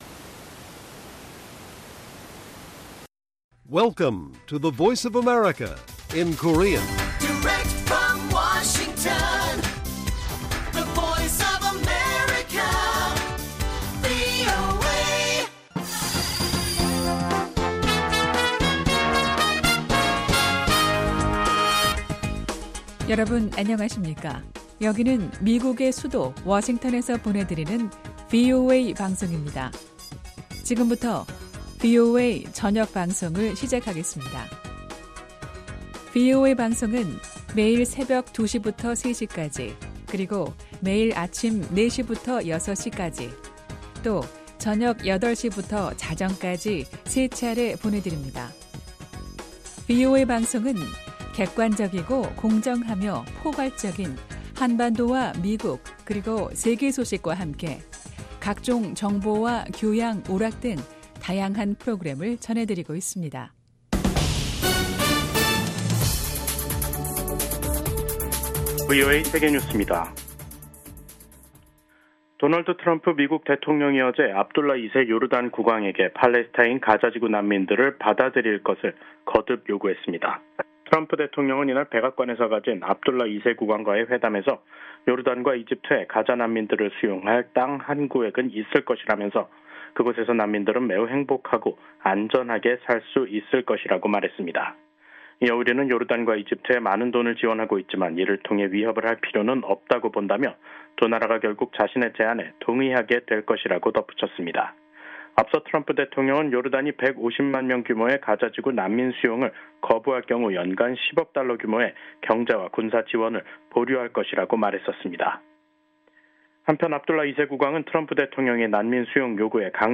VOA 한국어 간판 뉴스 프로그램 '뉴스 투데이', 2025년 2월 12일 1부 방송입니다. 도널드 트럼프 미국 대통령이 또 다시 김정은 북한 국무위원장과의 정상회담을 추진할 것임을 시사했습니다. 미국인 여성이 북한 정보기술(IT) 인력의 위장 취업을 도운 혐의를 인정했다고 미 법무부가 밝혔습니다. 미국 연방수사국(FBI)이 공개 수배 중인 대북제재 위반자가 최근 급증 양상을 보이고 있습니다.